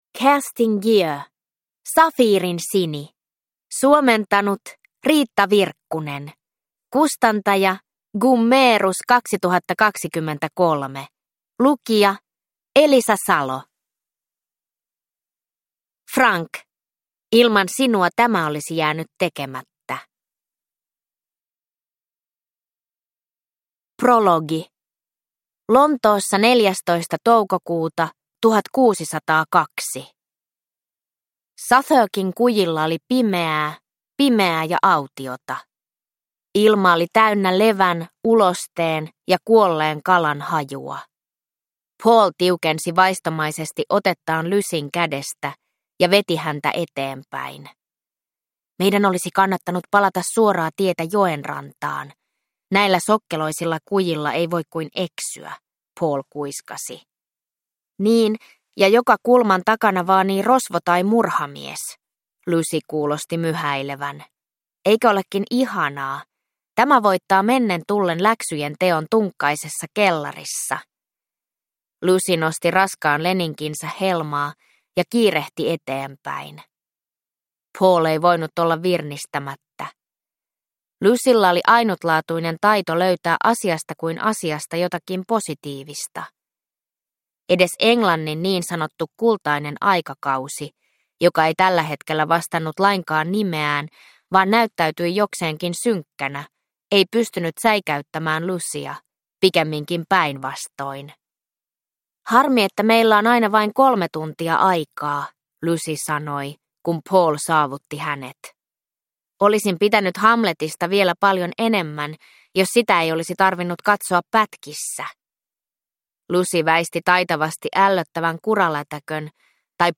Safiirinsini – Ljudbok